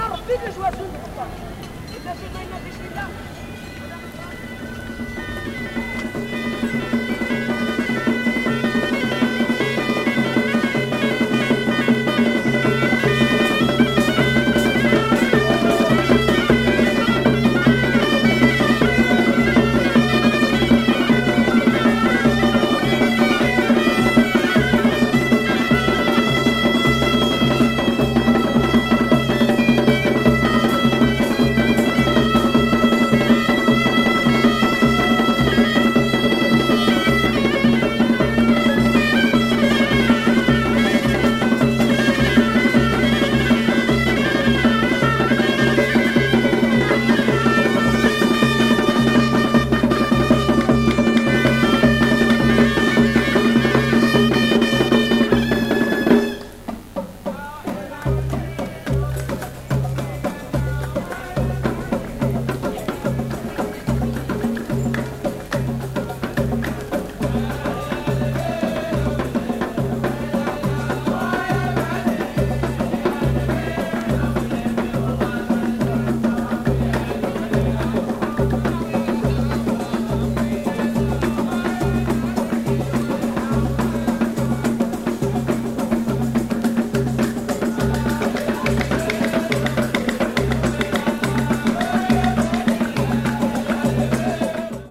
トランシーでサイケデリック、それでいながらどこか神秘的なグナワ音楽の真骨頂を体感！